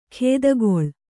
♪ khēdagoḷ